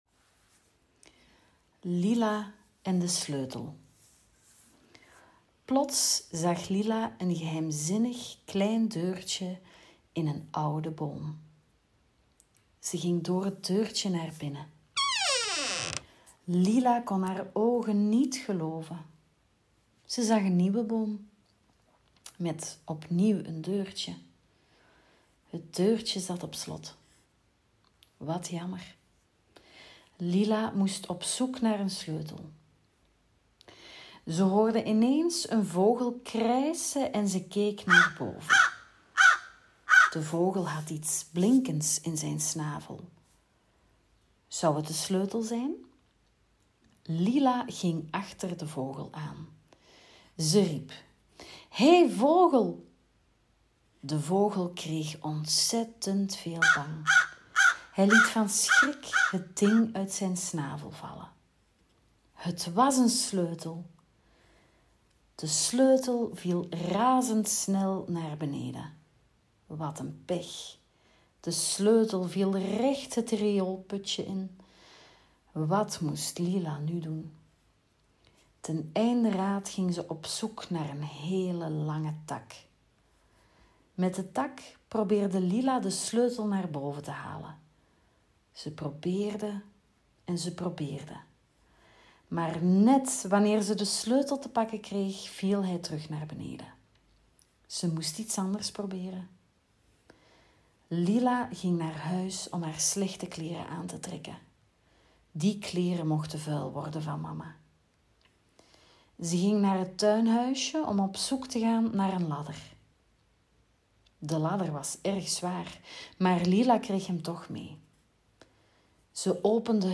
Er is gegild, gegierd, gerend en (voor)gelezen op de buitenspeeldag.
Ideaal voor het slapengaan!